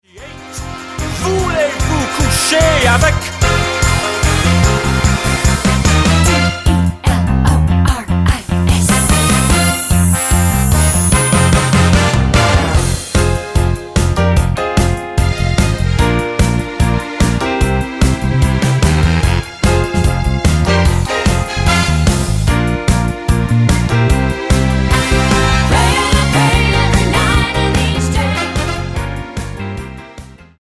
--> MP3 Demo abspielen...
Tonart:Fm mit Chor